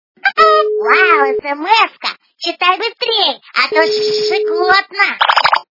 - звуки для СМС
При прослушивании Звонок для СМС - Вав, СМС-ка, читай быстрей... качество понижено и присутствуют гудки.